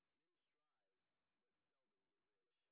sp06_street_snr20.wav